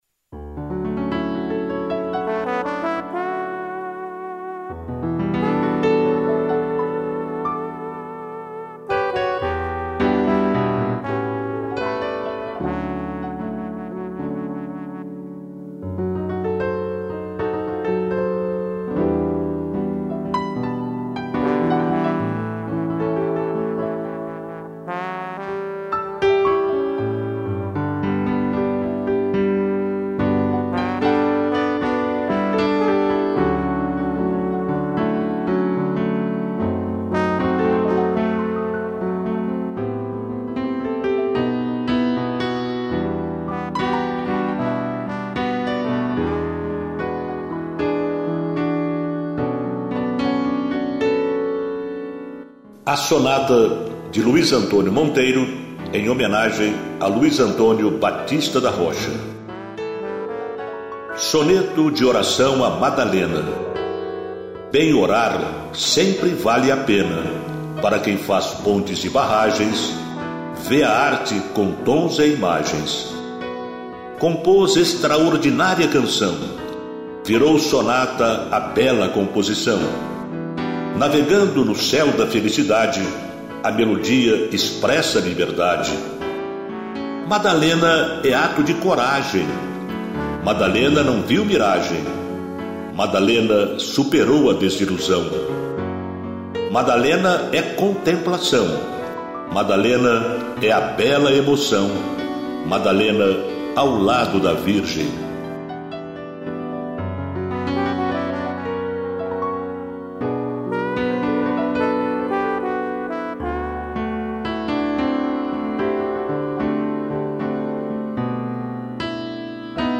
interpretação do texto